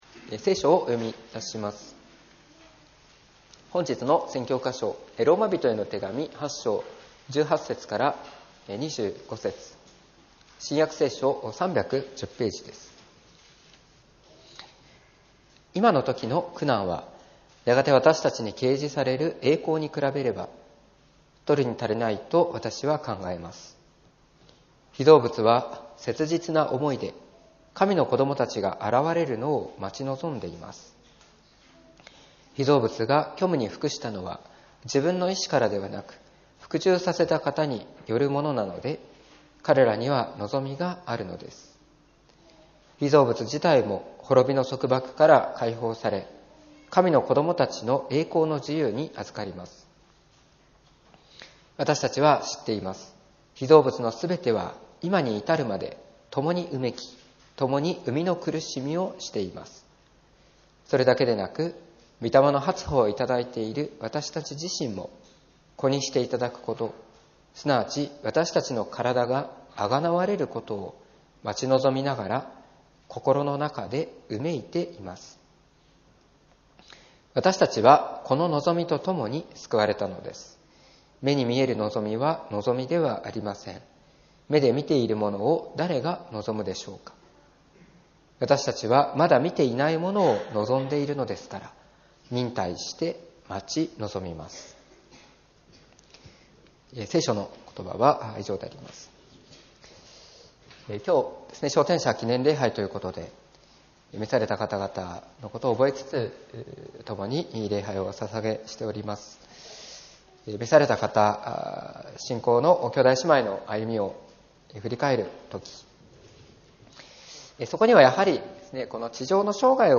2024年11月24日 礼拝説教「贖いを待ち望む」